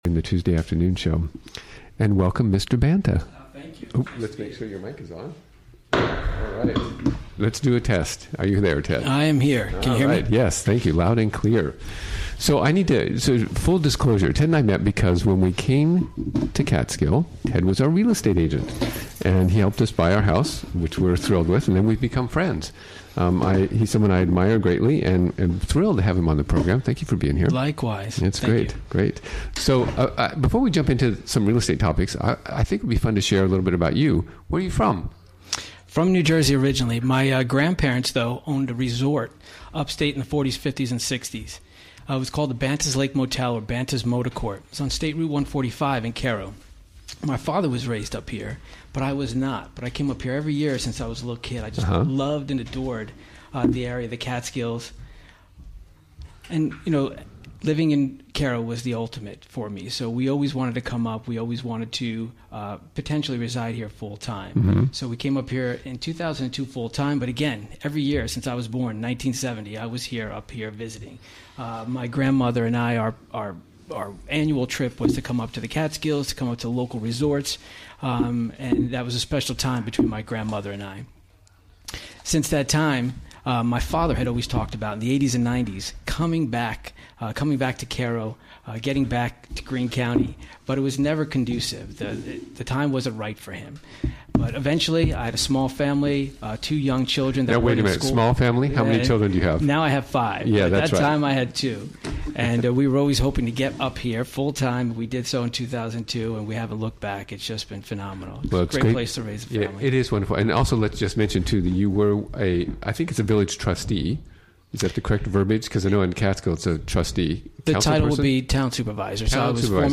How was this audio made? Recorded live during the WGXC Afternoon Show on Tuesday, May 2, 2017.